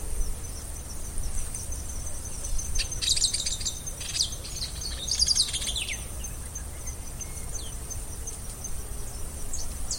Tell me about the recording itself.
Location or protected area: Reserva Natural del Pilar Condition: Wild Certainty: Photographed, Recorded vocal